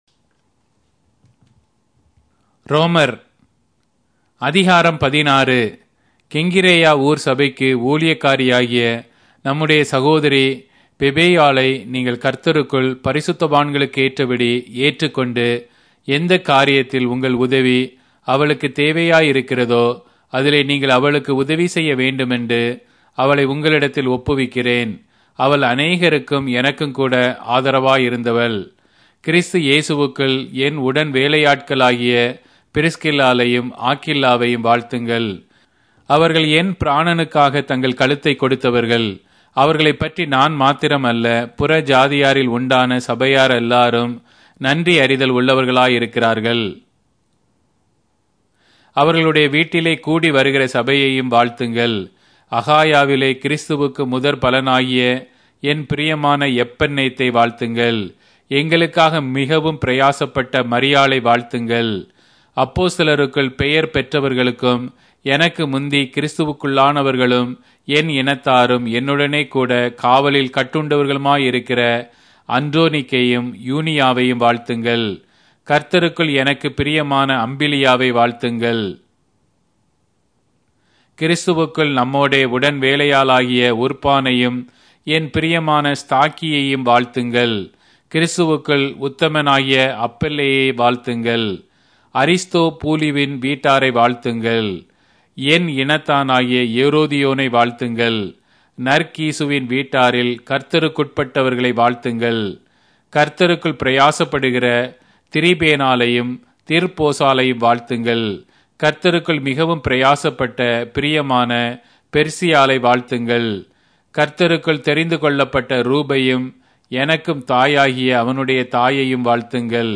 Tamil Audio Bible - Romans 15 in Tev bible version